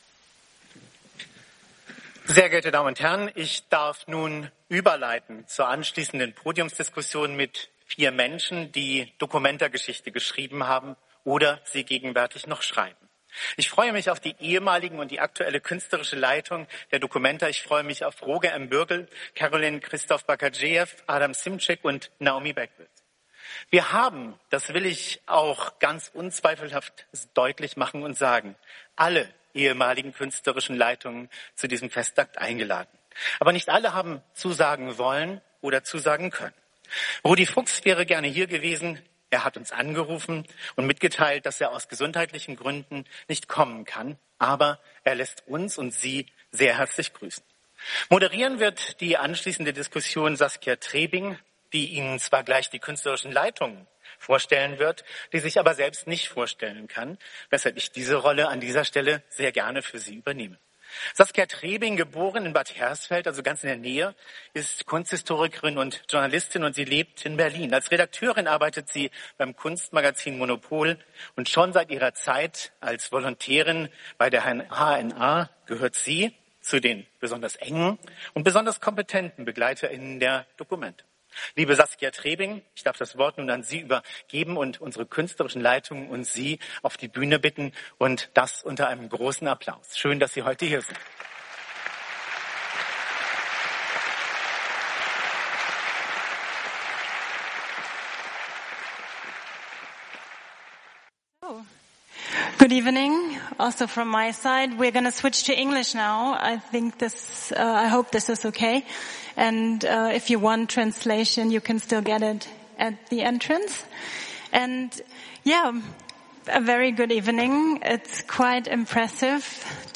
Freies Radio Kassel Wie geht die documenta mit ihrer eigenen Geschichte um – und welche Botschaften wollen ihre früheren Leiter senden? In dieser SprechZeit-Folge dokumentieren wir die Podiumsdiskussion vom 7. Juni 2025 in der documenta-Halle
sprechzeit-doku-documenta70-kuratorendiskussion_web.mp3